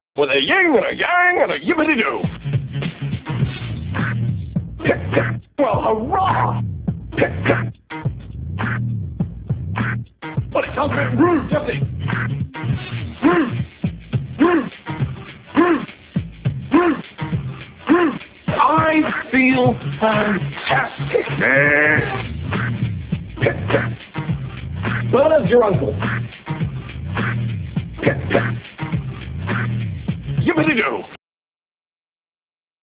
The file is 31 seconds long, 22,025 Hz, mono, 16-bit.